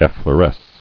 [ef·flo·resce]